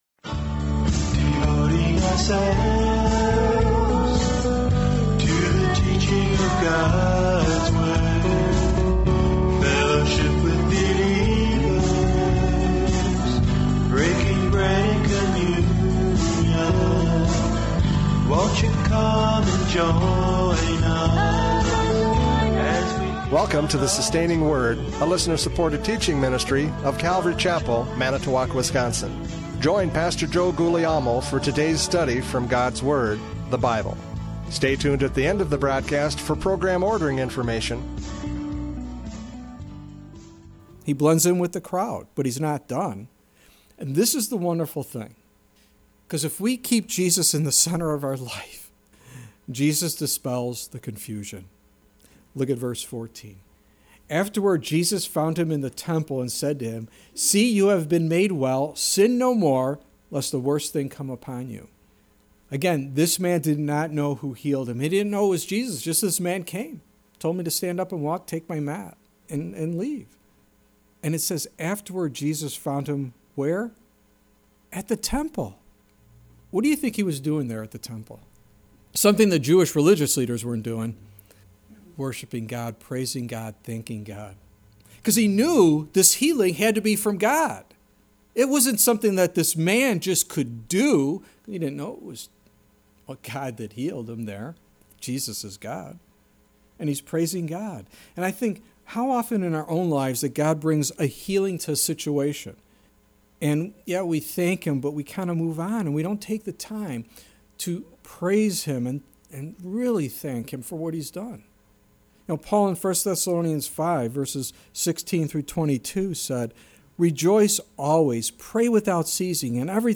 John 5:10-16 Service Type: Radio Programs « John 5:10-16 Legalism Kills!